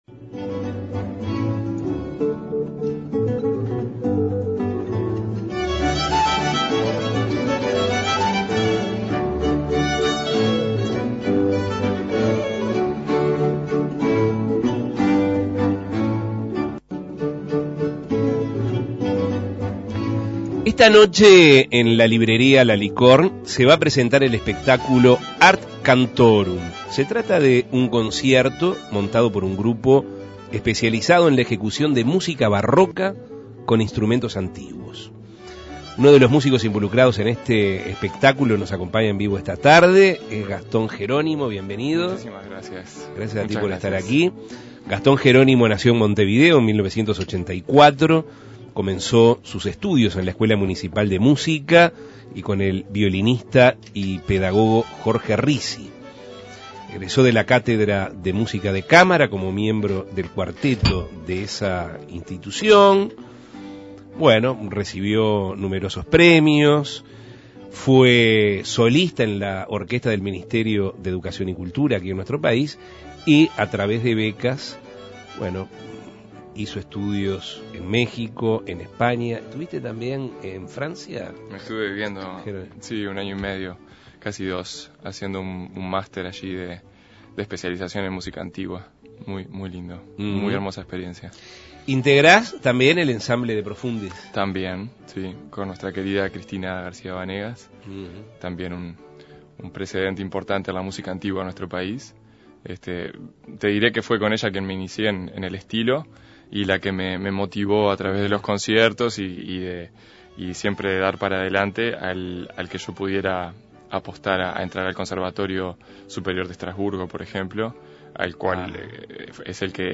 Entrevistas Música barroca en los tiempos que corren Imprimir A- A A+ Este viernes se presenta en la librería La Licorne el espectáculo "Art Cantorum".